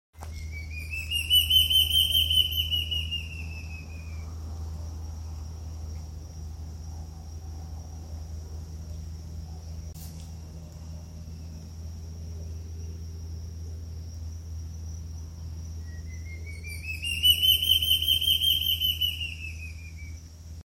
Large-tailed Antshrike (Mackenziaena leachii)
Location or protected area: Santa Ana
Condition: Wild
Certainty: Photographed, Recorded vocal